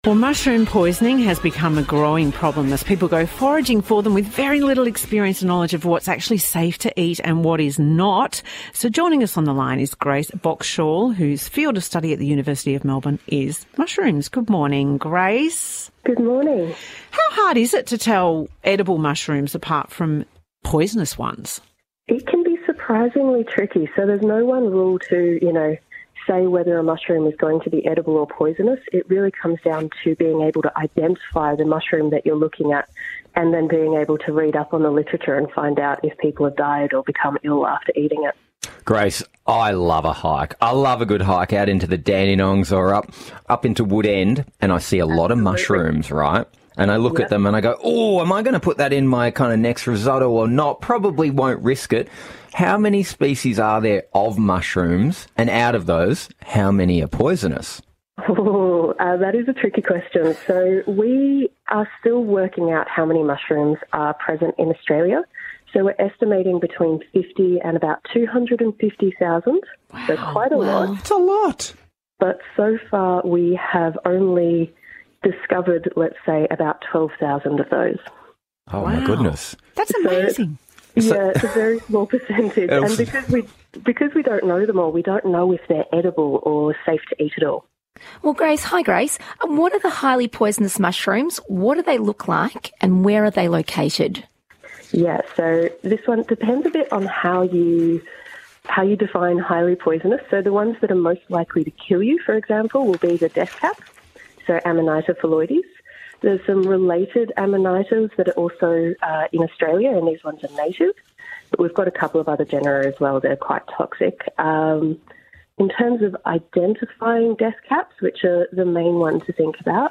talks to The House of Wellness radio team about identifying poisonous mushrooms